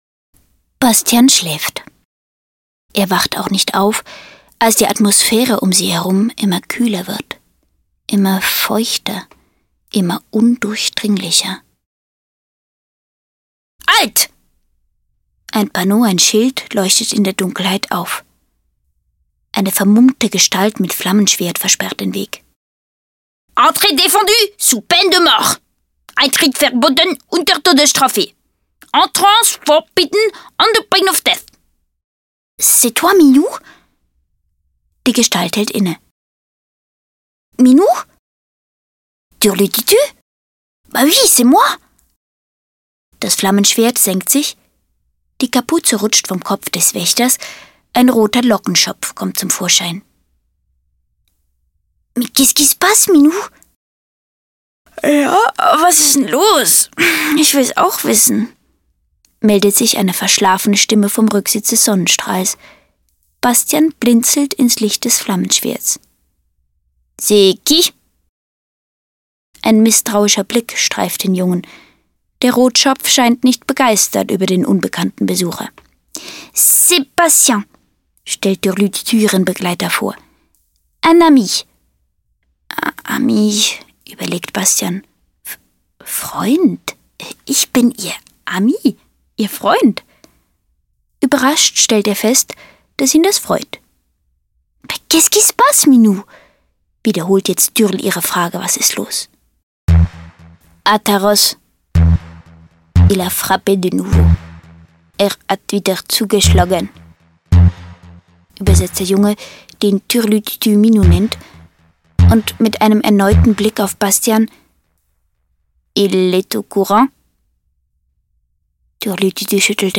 Das ganze Französischvokabular des ersten Lernjahrs verpackt in ein spannendes Hörspiel Ziel des Hörbuchs: Wiederholung oder Vorbereitung (fast) aller wichtigen Wörter, die im ersten Franz Lernjahr in der Schule im Buch auftauchen Zur Geschichte Bastian hat Französischunterricht.